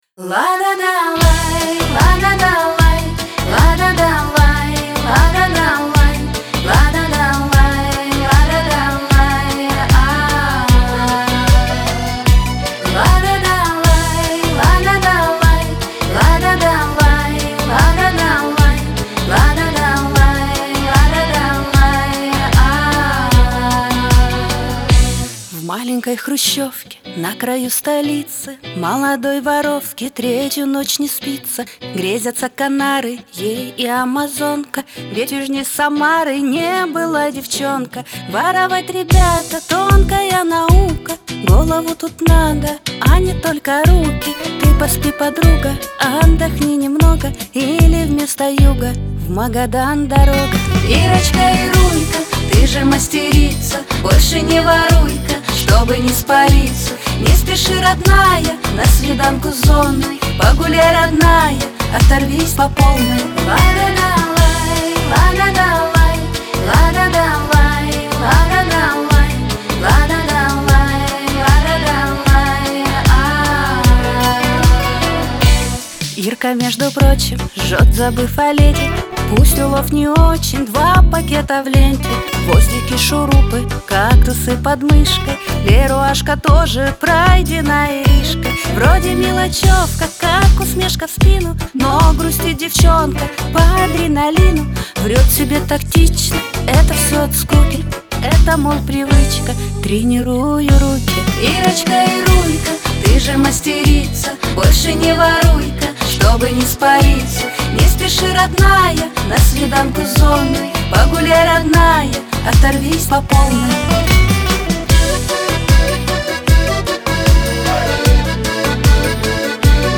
Лирика
грусть